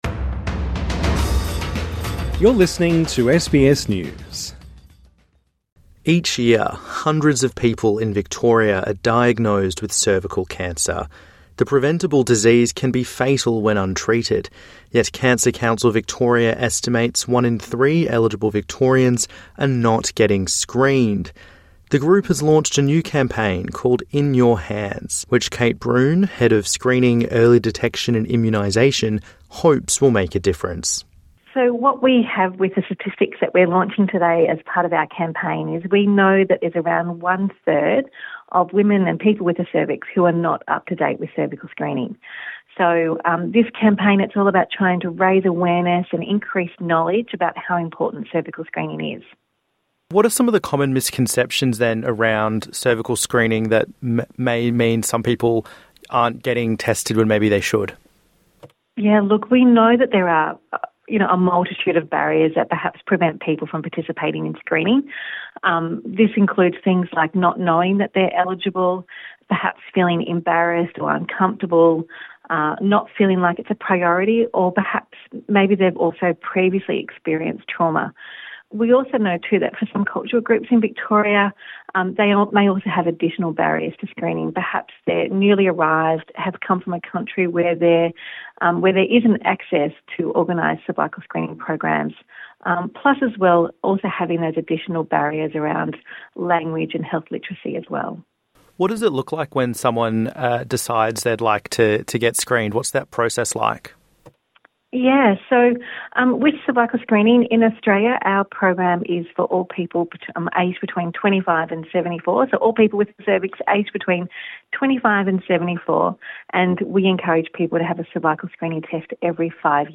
INTERVIEW: Hundreds die from preventable cervical cancer each year, what needs to change?